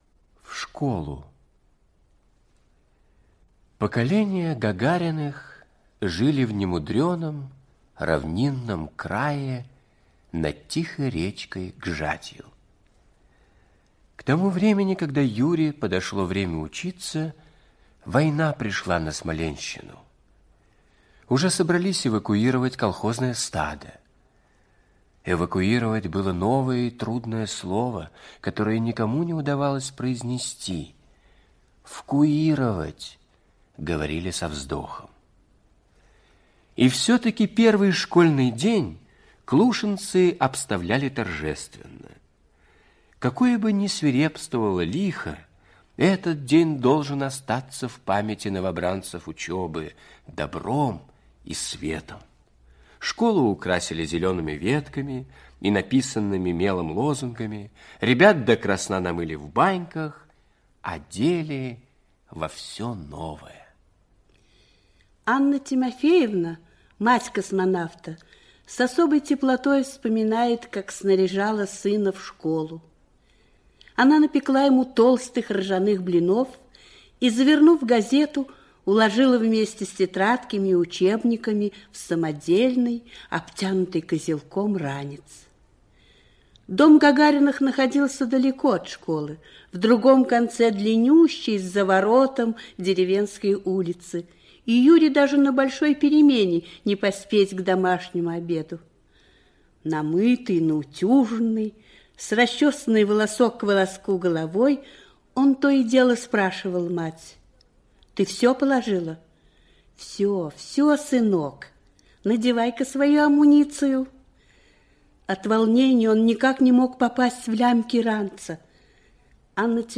В школу - аудио рассказ Нагибина - слушать онлайн